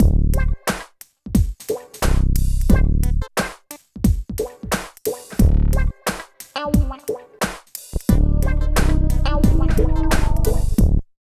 95 LOOP   -L.wav